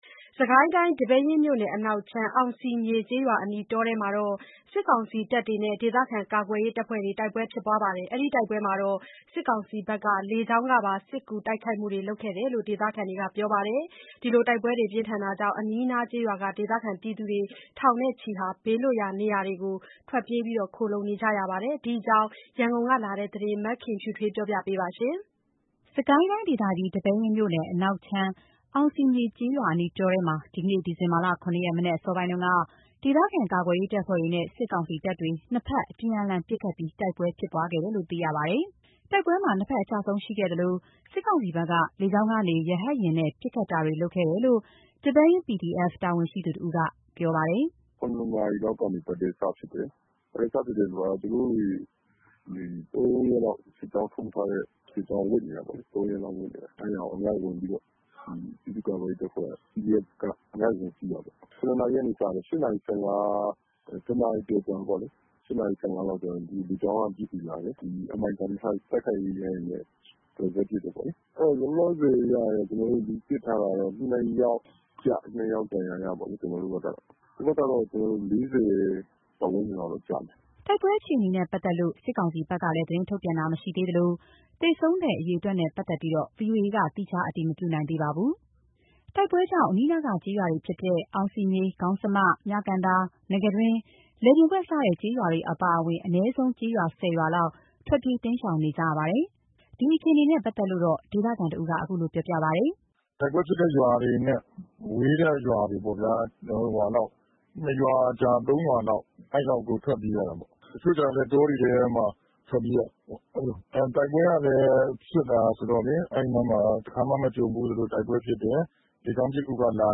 by ဗွီအိုအေသတင်းဌာန